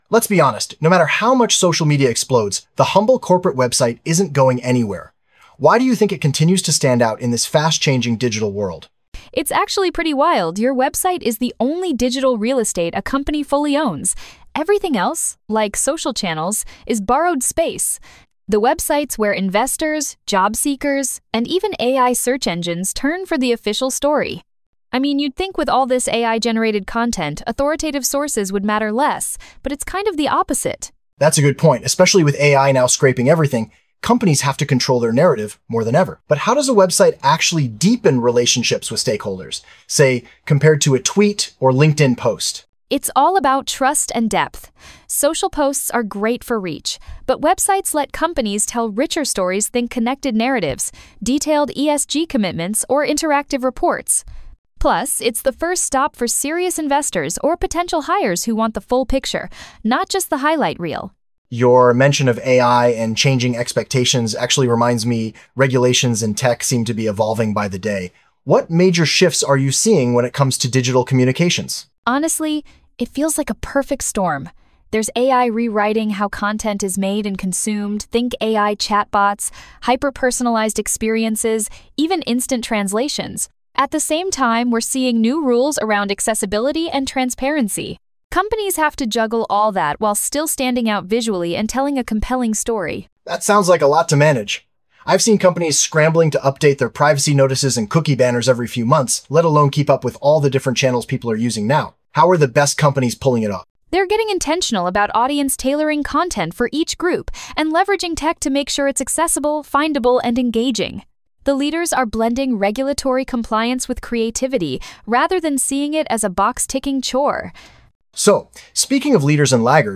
Discussion of our Digital Index website research 2025
Black Sun's AI-generated Digital Index Podcast.